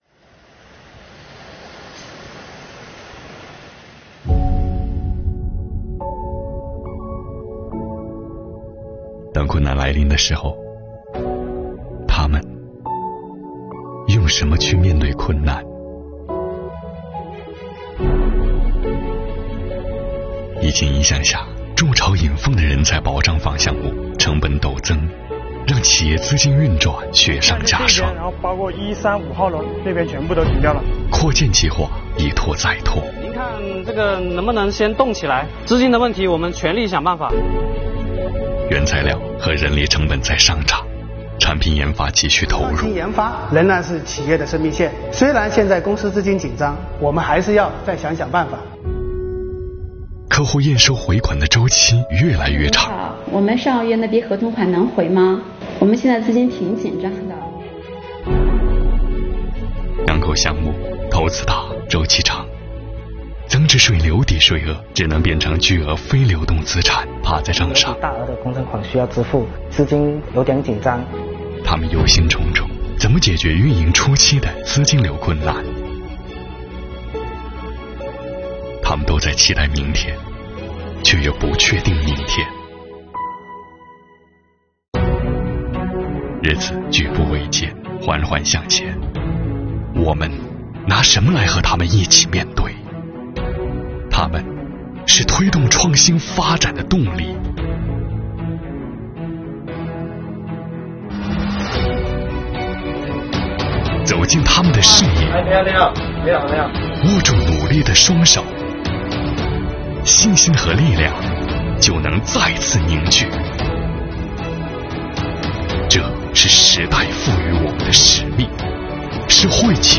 作品讲述了企业面对资金压力等困难时，税务部门主动上门服务，积极落实各项退税减税政策，为企业纾困解难，展示了税企同心、共克时艰的主题。作品场景丰富，运动镜头与特写镜头搭配得当，音乐与故事情节相得益彰。